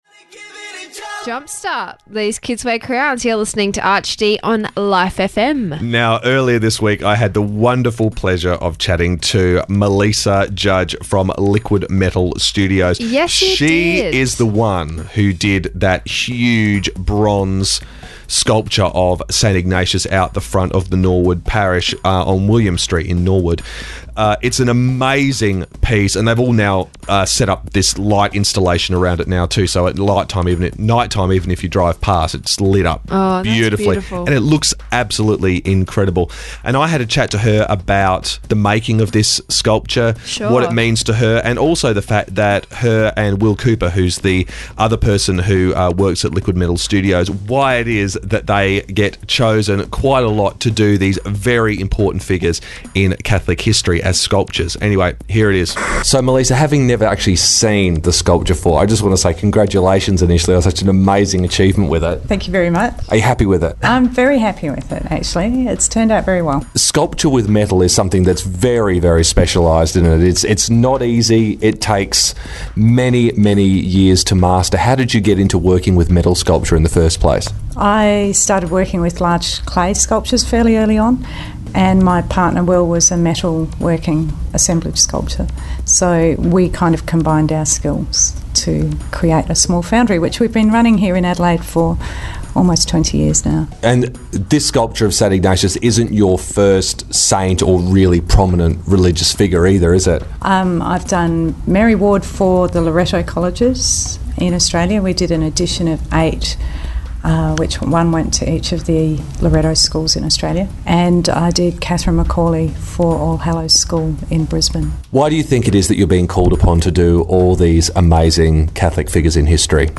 RADIO INTERVIEW: Arch D radio;